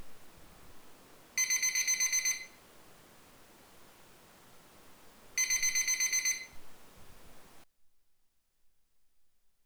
Noise Performance Test Sound Files
In order to evaluate the noise performance of the various recorders in combination with different microphones, the sound of an electronic alarm clock has been recorded subsequently with three different microphones and various recorders.
The test arrangement was fixed throughout the entire test procedure (the distance between the clock and the microphones was 1 meter).
The last two seconds of each file represent the inherent noise floor of the recorder (the microphone input was terminated by a 150 ohms resistor).
SoundDevices 722